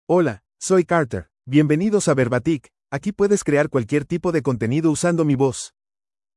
MaleSpanish (United States)
CarterMale Spanish AI voice
Carter is a male AI voice for Spanish (United States).
Voice sample
Carter delivers clear pronunciation with authentic United States Spanish intonation, making your content sound professionally produced.